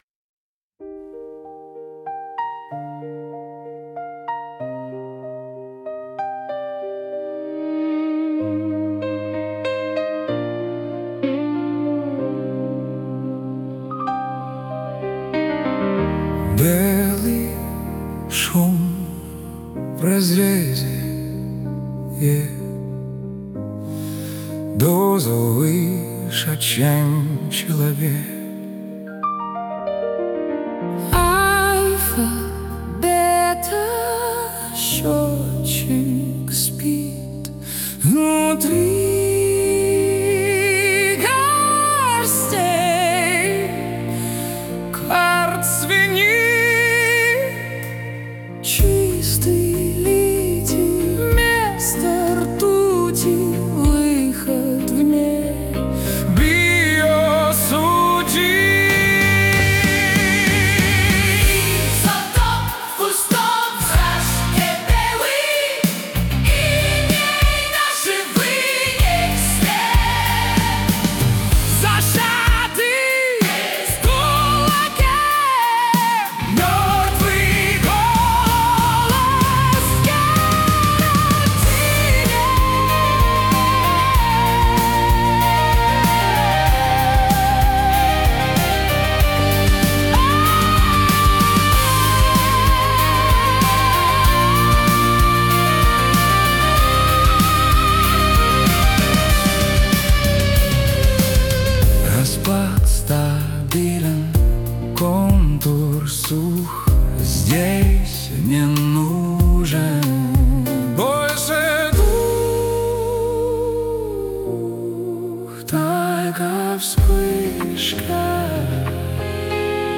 • Жанр: AI Generated
Power Noise Art-Pop